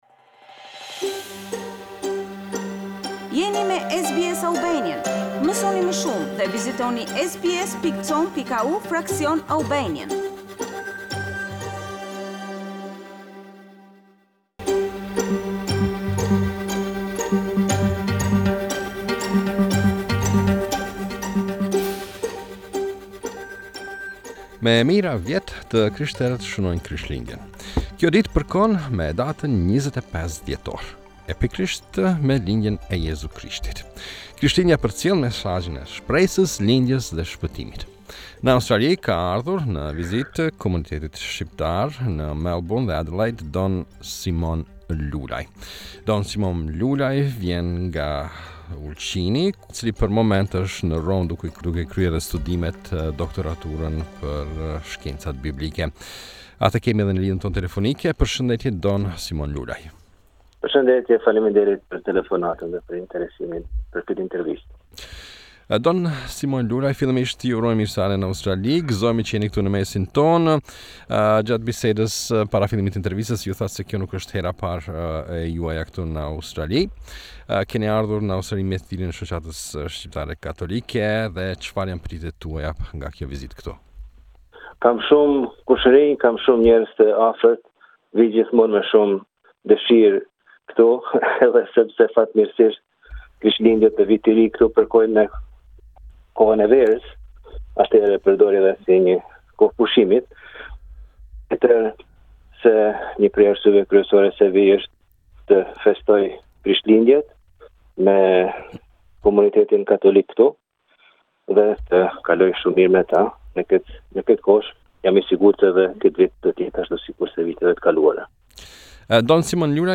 Ne i morrem atij nje interviste.